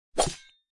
slash.mp3